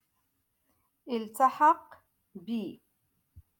Moroccan Dialect-Rotation Five-Lesson Sixty Two